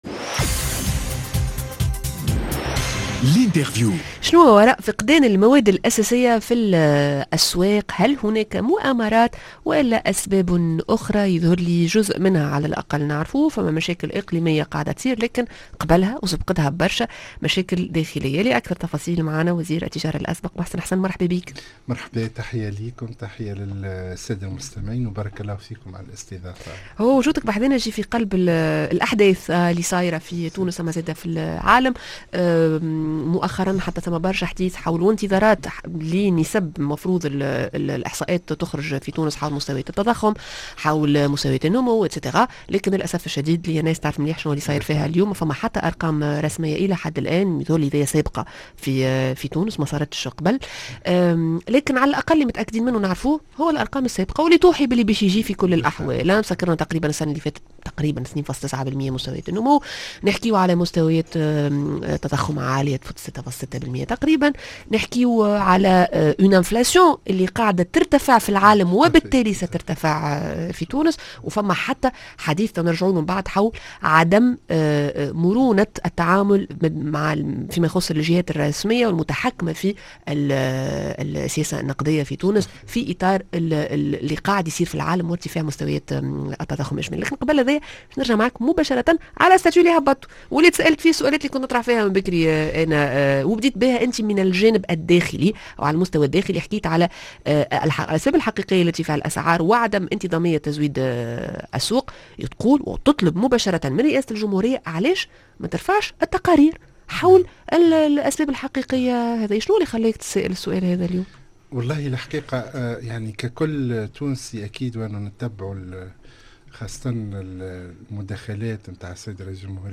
محسن حسن وزير التجارة الأسبق l_interview Eco_Mag